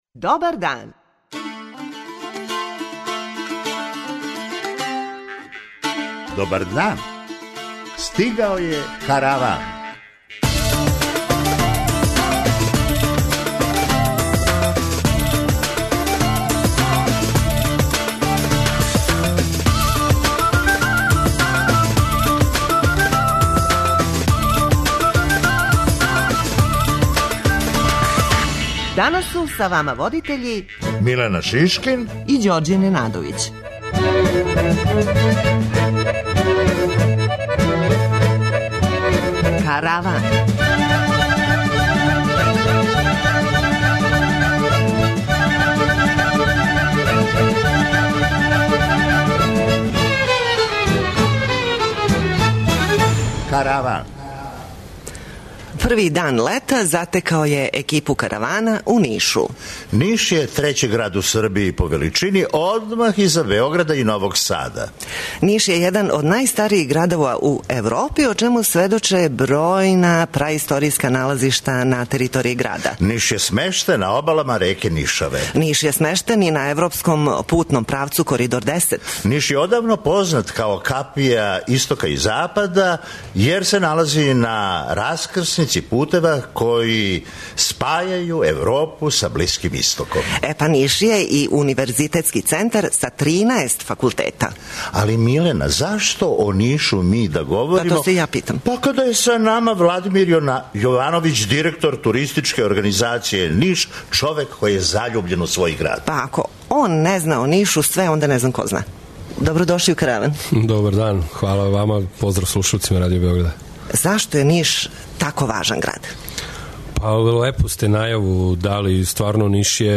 Караван емитујемо из Нишке Бање, у којој се и данас одвојено купају мушкарци и жене, што се сматра додиром стила, али је релативно непопуларно међу посетиоцима.
преузми : 23.28 MB Караван Autor: Забавна редакција Радио Бeограда 1 Караван се креће ка својој дестинацији већ више од 50 година, увек добро натоварен актуелним хумором и изворним народним песмама.